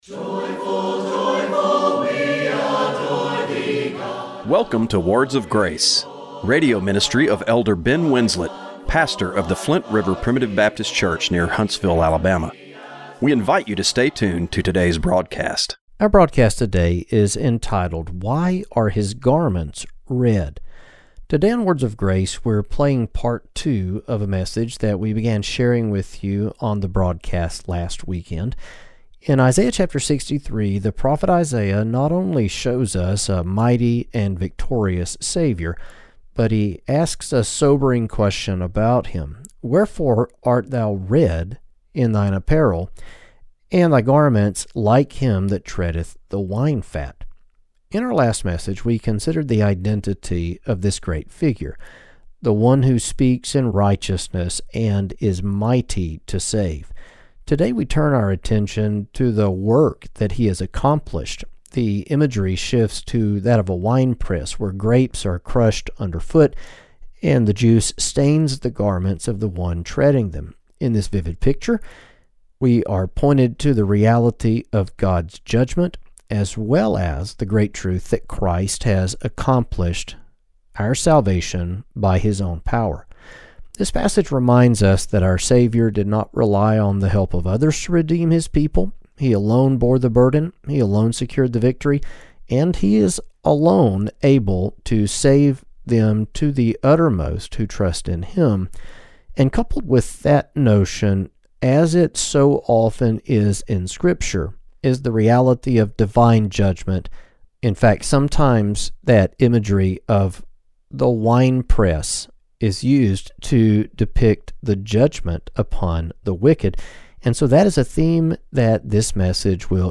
Radio broadcast for March 22, 2026.